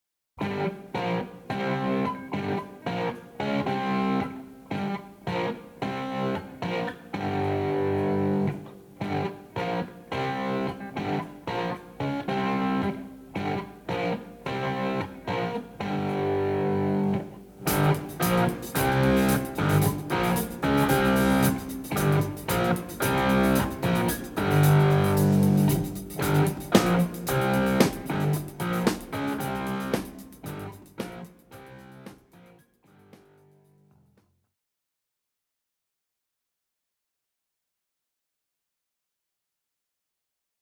E-Gitarre
08-E-Gitarre-verzerrt.mp3